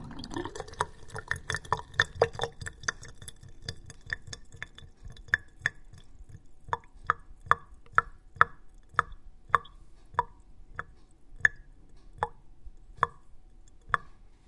淌水沟
描述：一个下雨天后，水滴入排水沟。记录在沟壑的上部。
标签： 污水 街道 雨水 沟壑 漏极
声道立体声